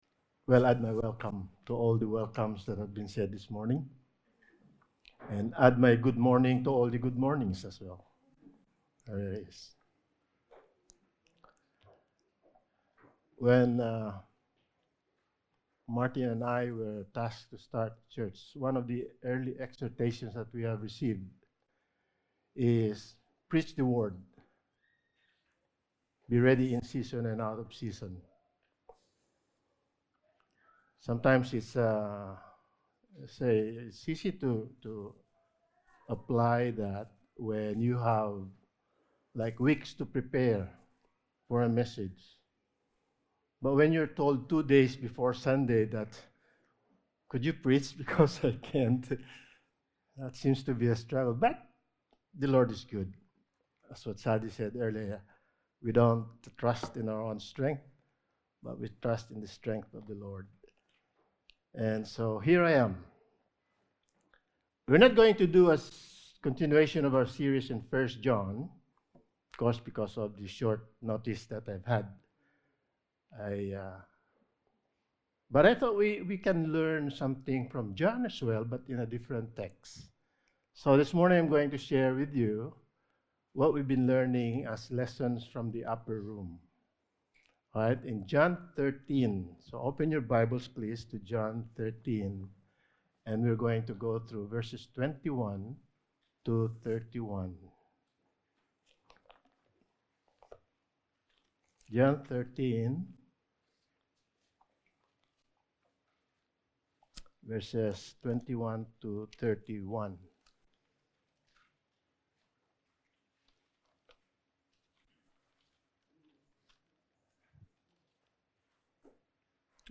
Topical Sermon Passage: John 13:21-31 Service Type: Sunday Morning